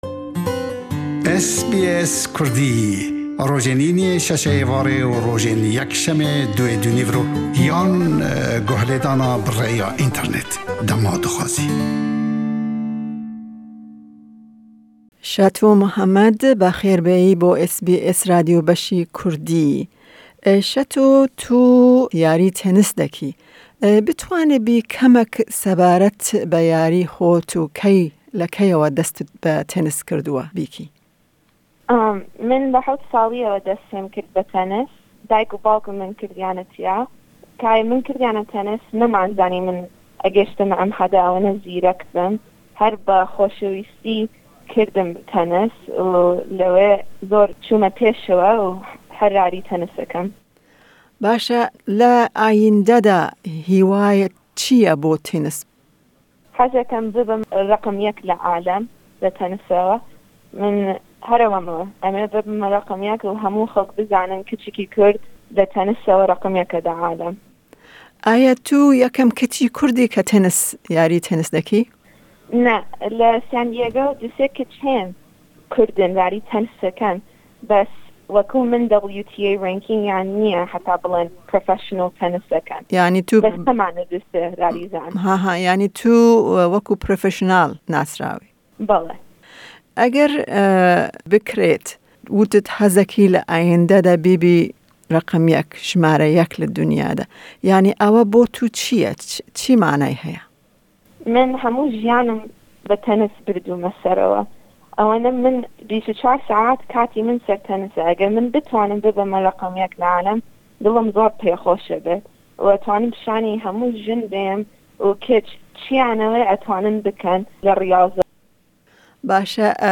Me kurte hevpeyvîneke bi zimanî Kurdî bi wê re pêk anî, lê di rojên li pêş de emê hevpeyvîna bi zimanî Înigilîzî jî belav bikin.